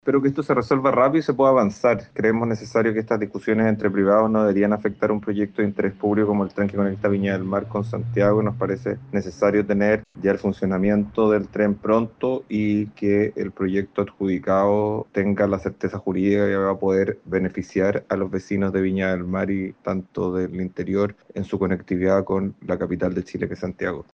Por otro lado, el senador socialista, Tomás de Rementería, enfatizó que el conflicto debe resolverse con celeridad para no retrasar un proyecto de interés público para la zona.